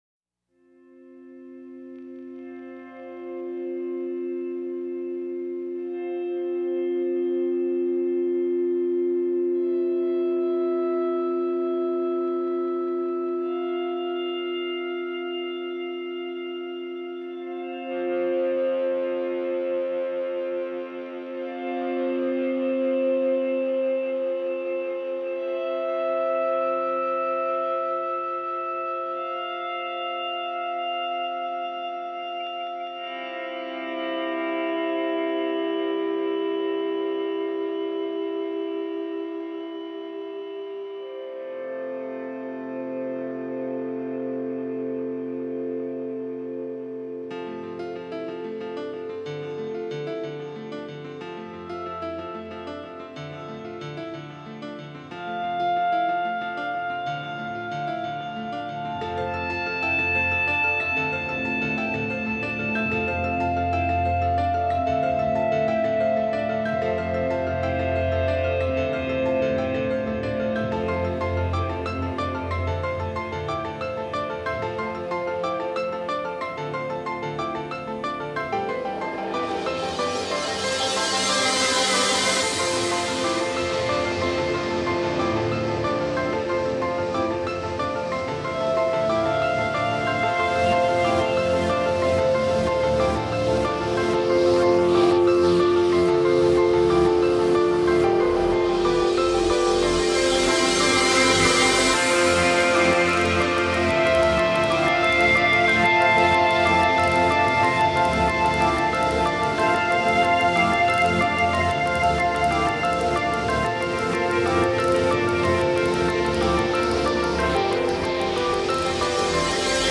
both dreamy songs with a constant, leisure pace.